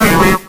Audio / SE / Cries / BELLSPROUT.ogg